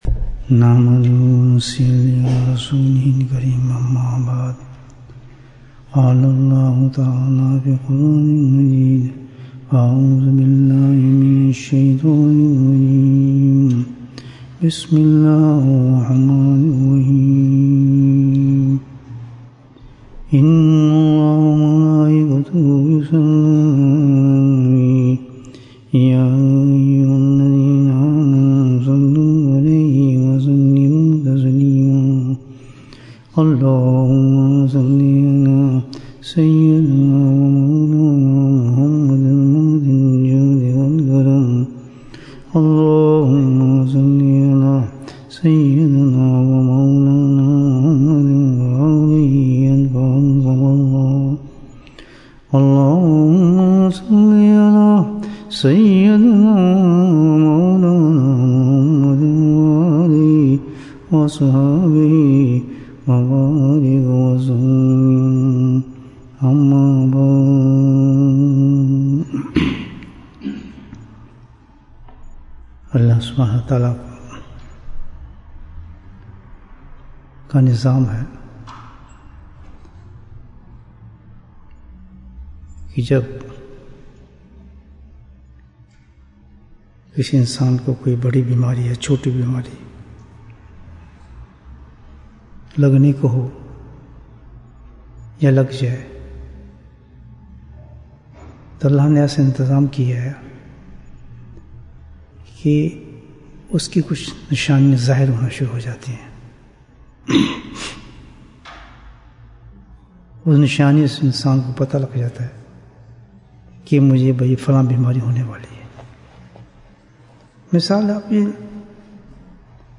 دل کی بیماری کی نشانیاں Bayan, 33 minutes13th August, 2020